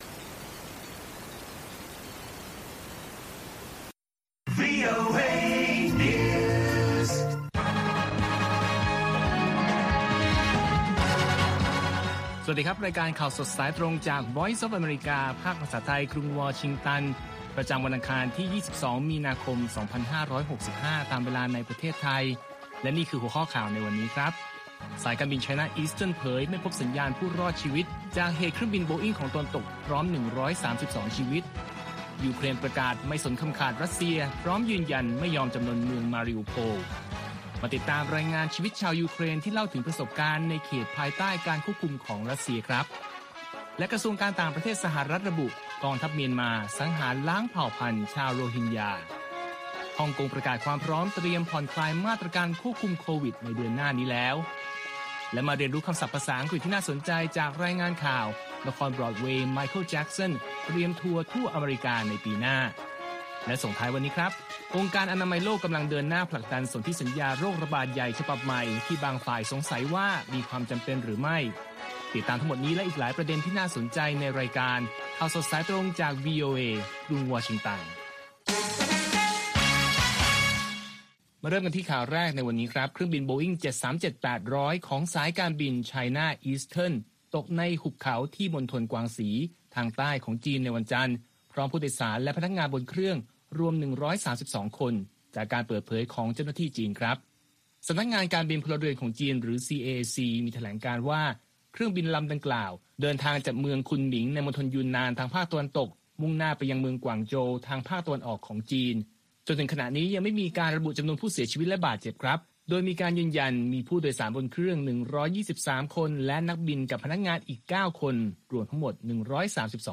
ข่าวสดสายตรงจากวีโอเอ ภาคภาษาไทย ประจำวันอังคารที่ 22 มีนาคม 2565 ตามเวลาประเทศไทย